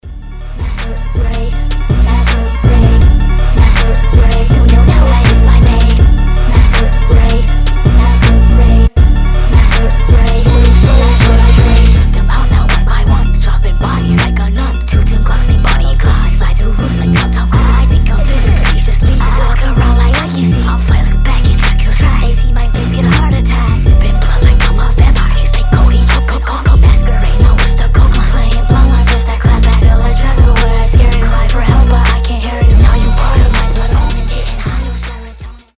• Качество: 320, Stereo
пугающие
alternative
необычные
glitch hop